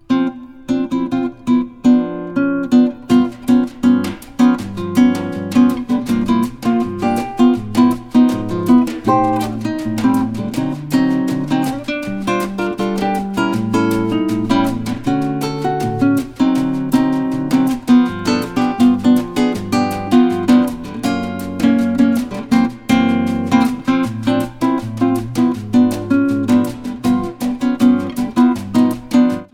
guitars
percussions